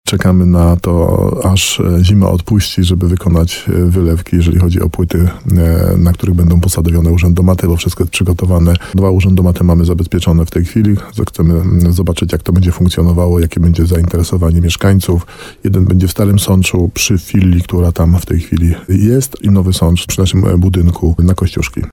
– mówi starosta nowosądecki Tadeusz Zaremba.